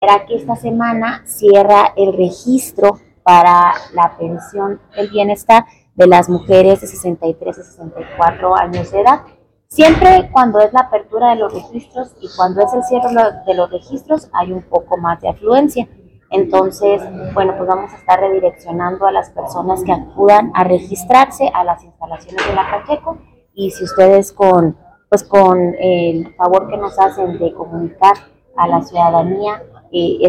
AUDIO: MAYRA CHÁVEZ, DELEGADA EN CHIHUAHUA D LA SECRETARÍA DE BIENESTAR